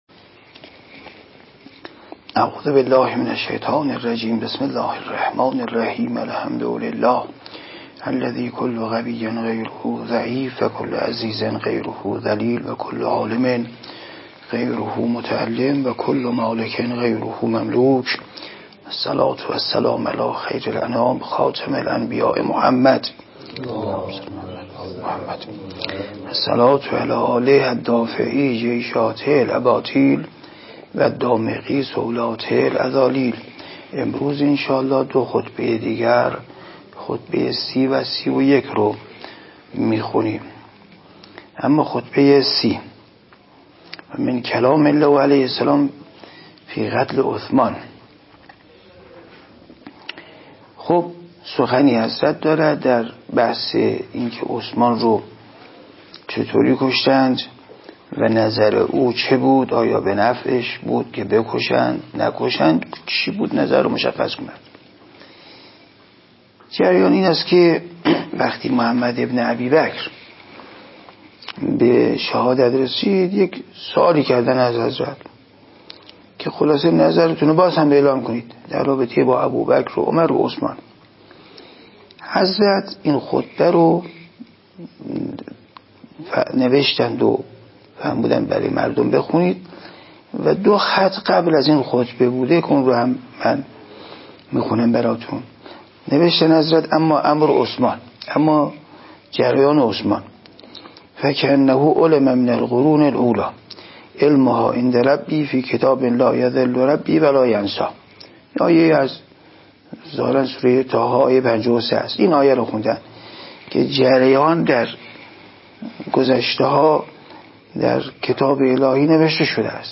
0 – شرح صوتی مقدمه ، جوهره و خلاصه نهج‌البلاغه – بنیاد مجازی نهج البلاغه * تاریخچه کار سال ۱۳۵۸ سوالاتی ذهنم را سخت مشغول کرد.